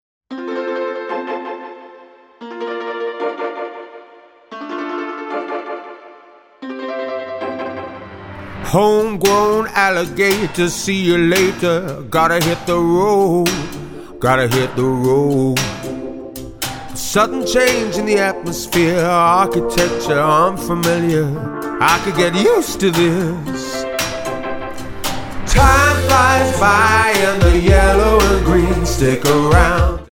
--> MP3 Demo abspielen...
Tonart:F Multifile (kein Sofortdownload.